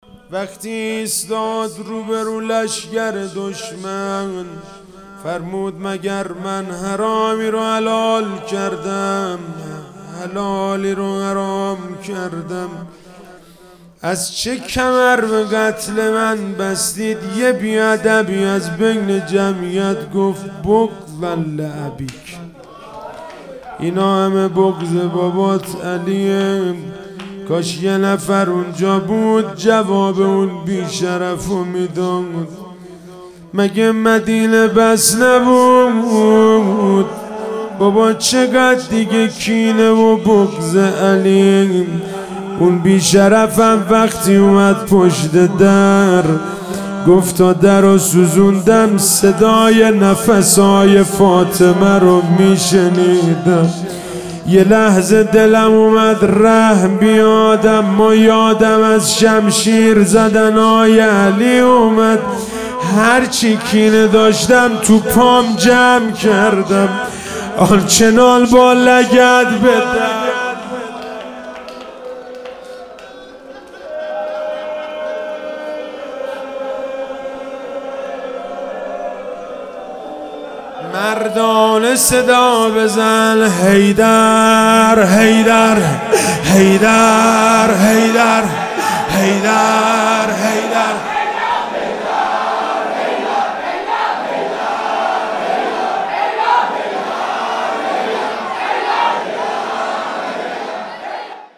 روضه حضرت زینب سلام الله علیها